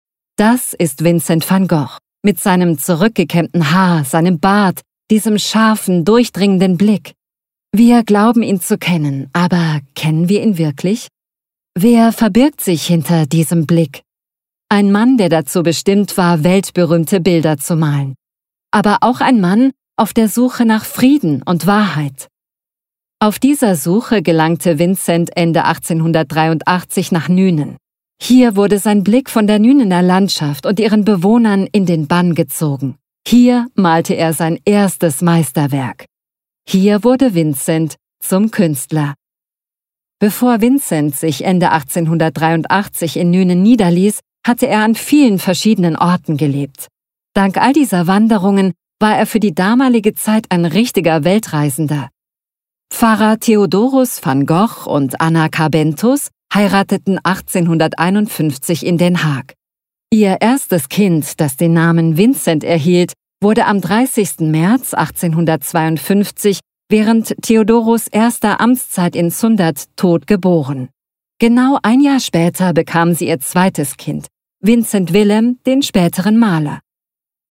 Audio Guides
• Mikrofon: Neumann TLM 67 / Neumann TLM 103
• Acoustic Cabin : Studiobricks ONE
ContraltoMezzo-Soprano
ConfidentDynamicExperiencedFriendlyReliableTrustworthyVersatileYoungWarm